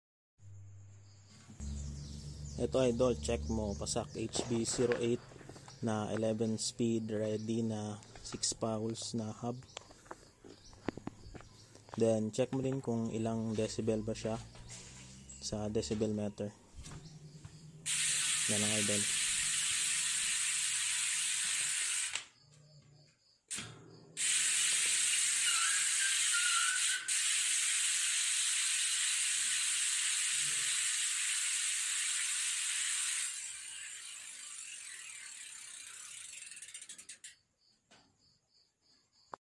08 Cassett hub 6 pawls hub